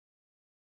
fireball.mp3